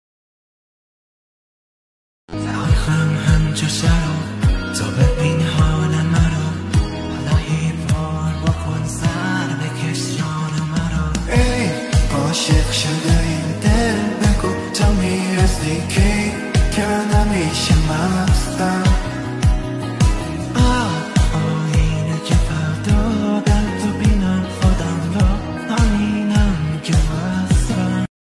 با صدای خواننده کره ای
(هوش مصنوعی)